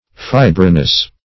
Meaning of fibrinous. fibrinous synonyms, pronunciation, spelling and more from Free Dictionary.
fibrinous.mp3